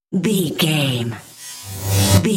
Riser sci fi electronic flashback
Sound Effects
Atonal
futuristic
intense
tension